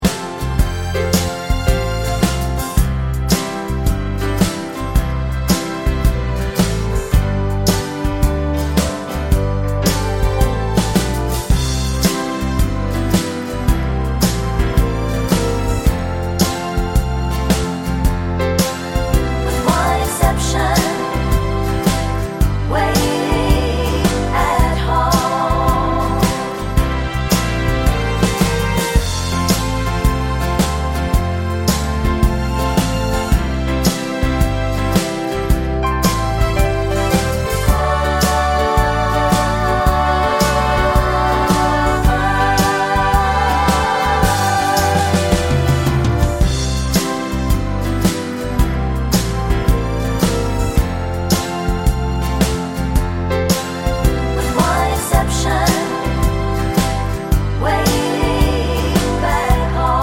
no Backing Vocals Crooners 3:05 Buy £1.50